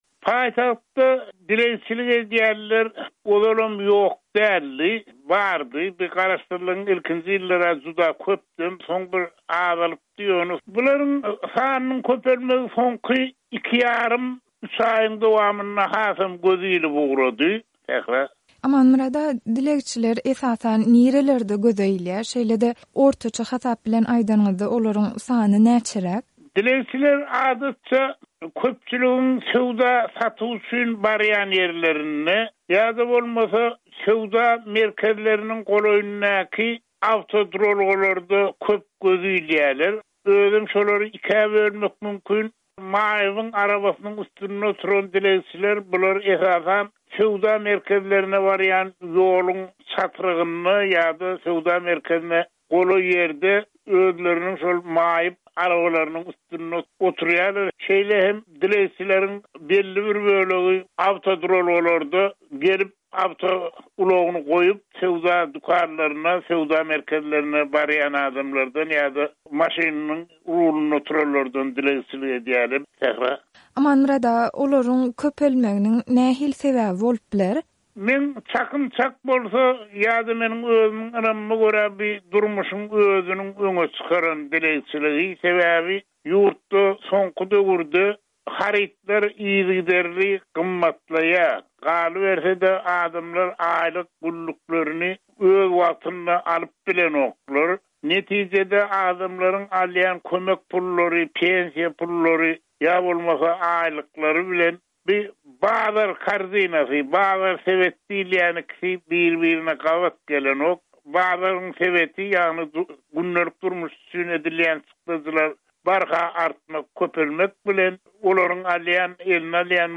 Azatlyk Radiosy bu barada gyzyklanyp, aşgabatly synçy